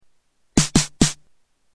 JOMOX XBase09SE Snare
Category: Sound FX   Right: Personal